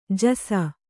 ♪ jasa